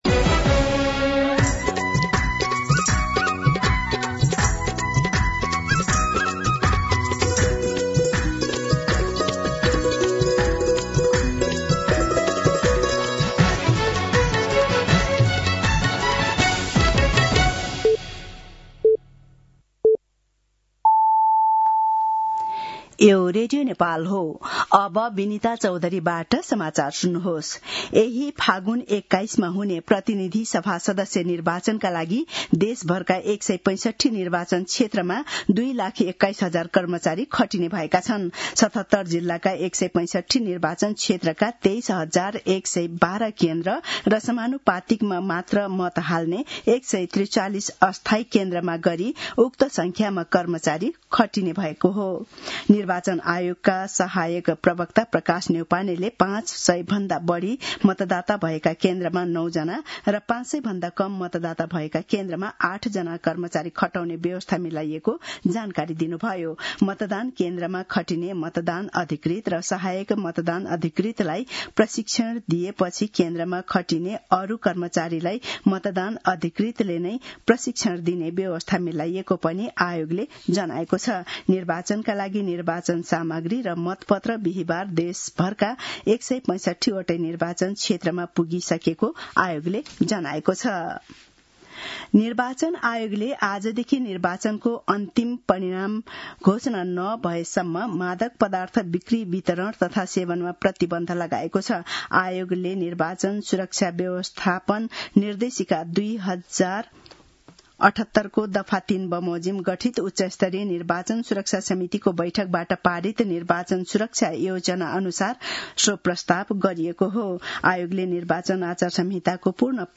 दिउँसो १ बजेको नेपाली समाचार : १५ फागुन , २०८२
1pm-News-11-15.mp3